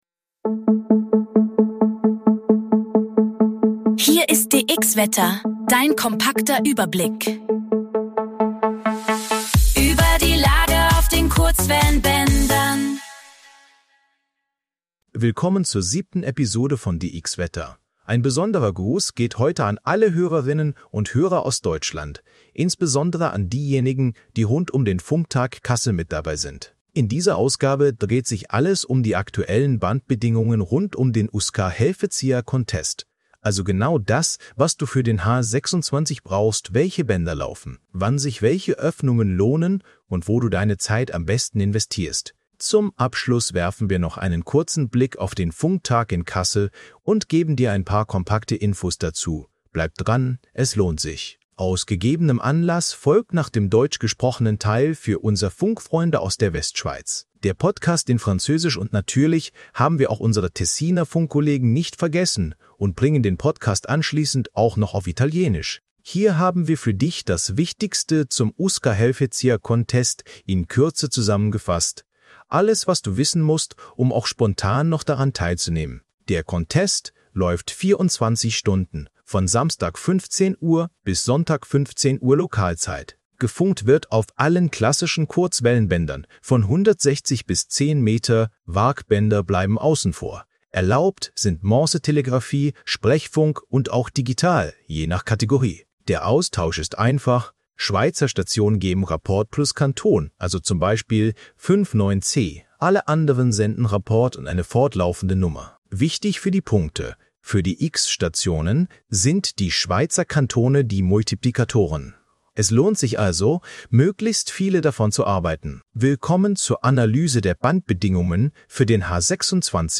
Beschreibung vor 1 Tag KI-generierter Inhalt - aktuell für die Region Schweiz (anlässlich des H26-Contest) und die Region Kassel (anlässlich des Funk.Tag in Kassel). DX WETTER liefert dir die aktuelle Lage auf den Kurzwellenbändern.